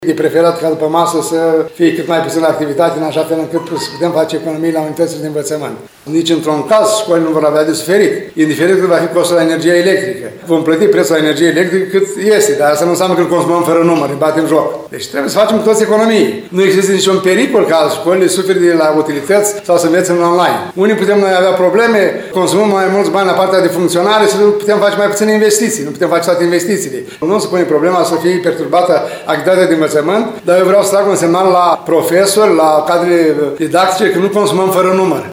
El a declarat astăzi că se impun economii în acest domeniu, deoarece școlile sunt mari consumatoare de utilități plătite din bani publici.